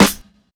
BAZ_SGH_SNR.wav